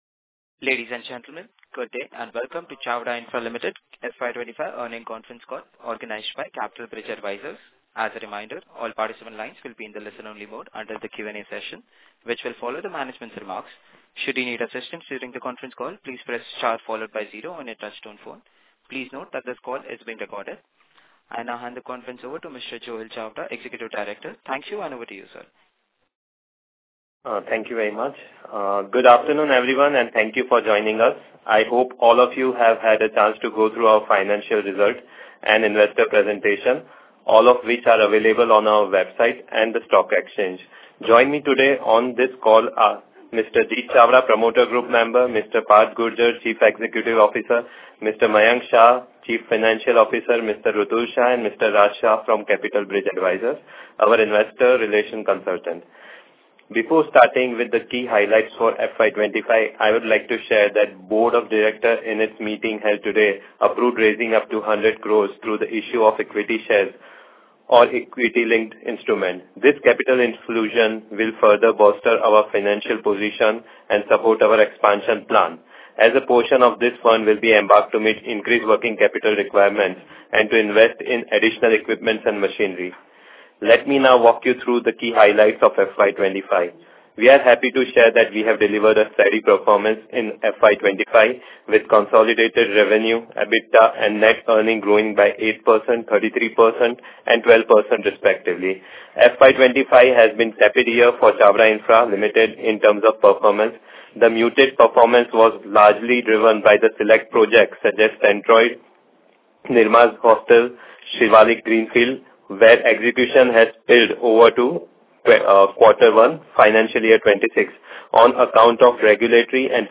EARNING-CALL-AUDIO-SCRIPT-FY-2025.mp3